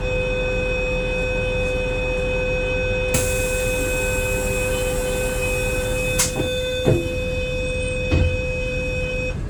I want the cleaner version of DSB IC3 train's Non Refub Door Closing Tone